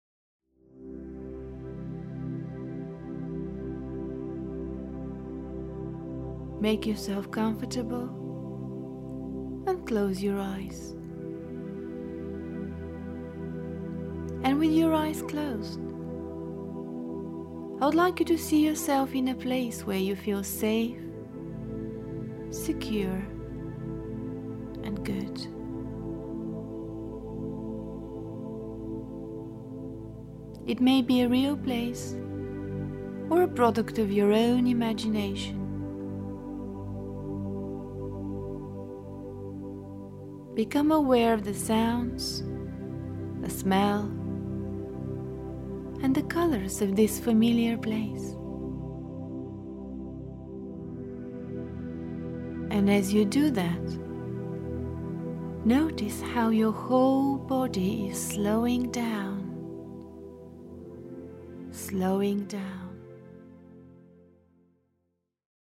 Genre: Trance.
Hypnosis for Relaxation